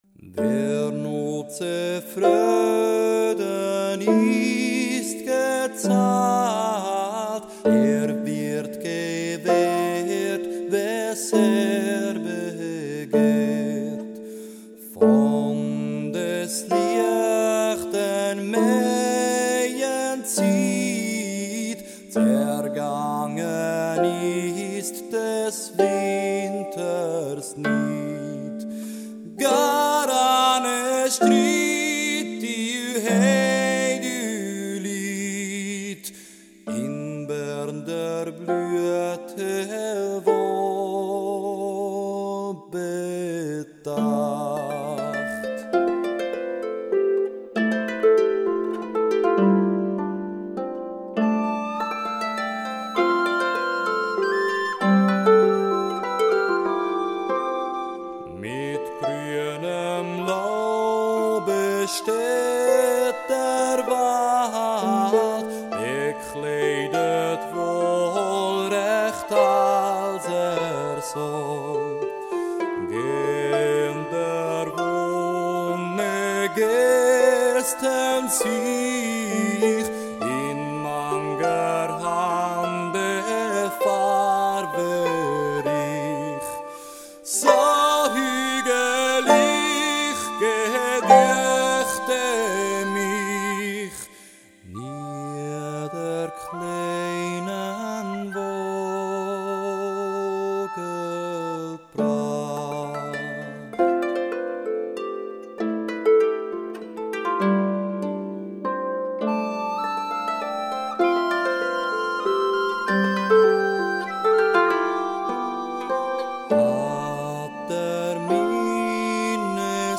Minnesang
wer nu ze fröden ist gezalt (Studioversion)